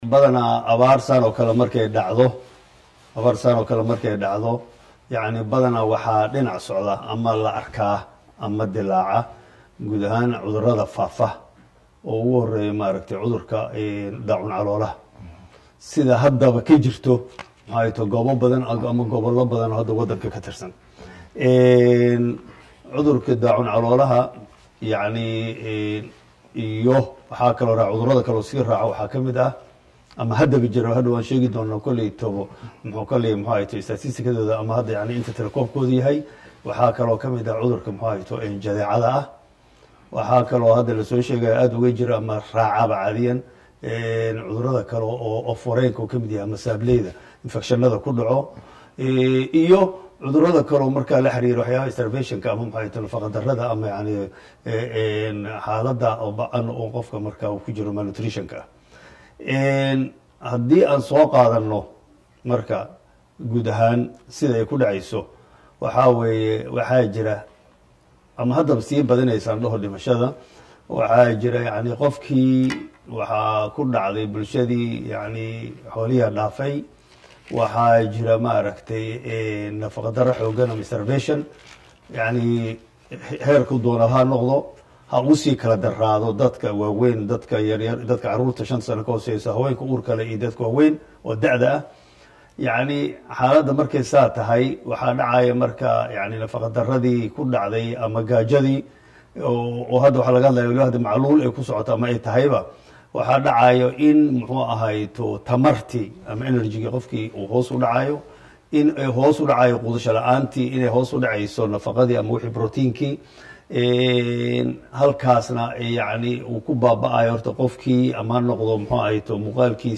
Dr Dufle oo u waramay Warbaahinta Qaranka tilmaamay in abaarta ay keentay cuduro badan oo halis ah, bulshadana looga baahanyahay inay qaadato talooyinka dhaqaatiirta oo ay ka fogaadaan waxkasta oo keeni kara cudurada faafa.